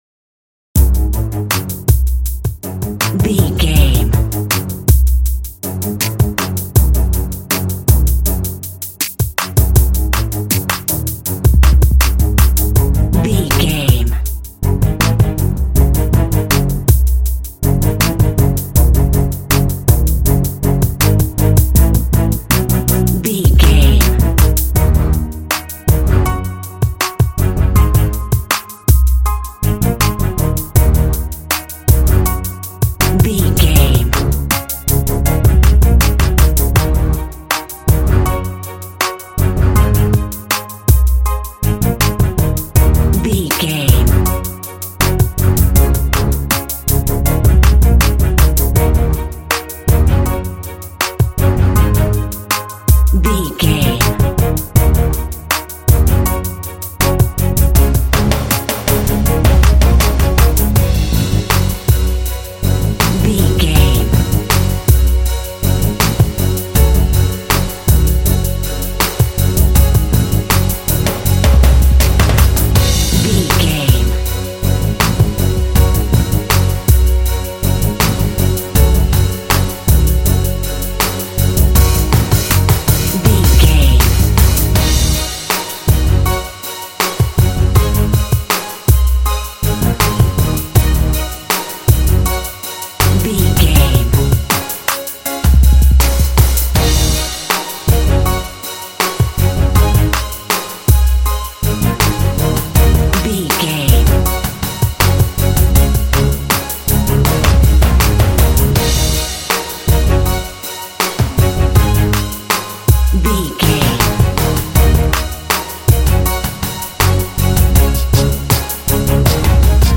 Fast paced
Aeolian/Minor
F#
ominous
tension
drums
strings
piano
cinematic